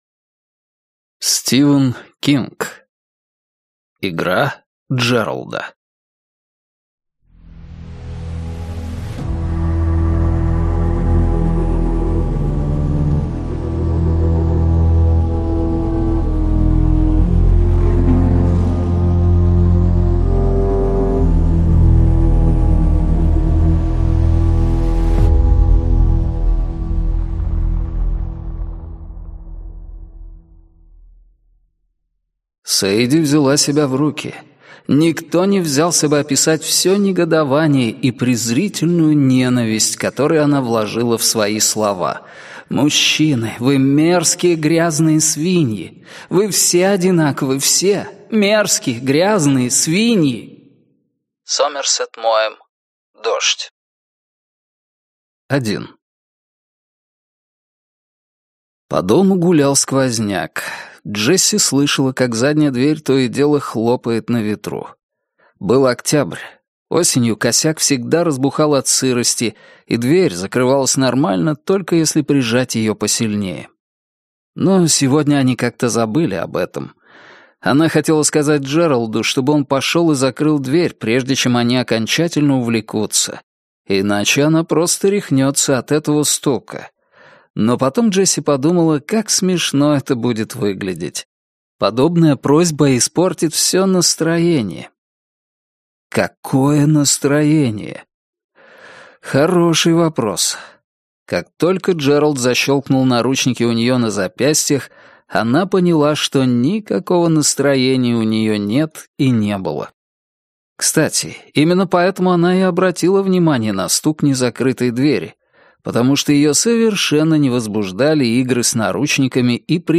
Аудиокнига Игра Джералда - купить, скачать и слушать онлайн | КнигоПоиск